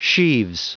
Prononciation du mot sheaves en anglais (fichier audio)
Prononciation du mot : sheaves